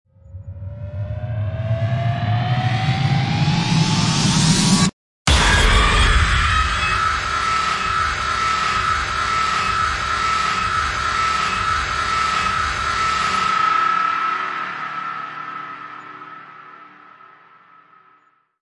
sawtooth.wav